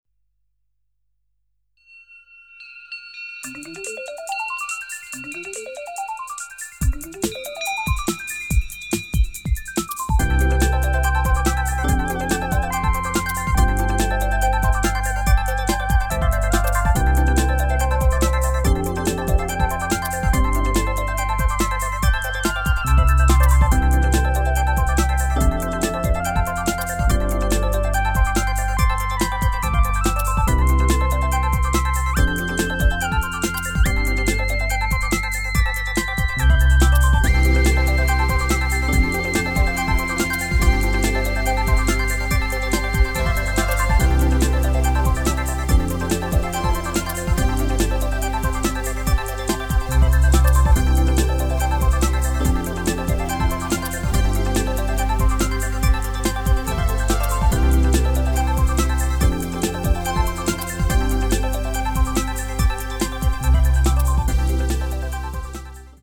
更にコーラス追加してみたり。
低域に唸りを与えることで、よりベースを強調出来ると踏んで。